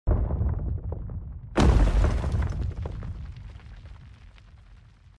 How Does The Krakatoa Volcano Sound Effects Free Download